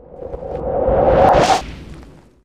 pux_blast.ogg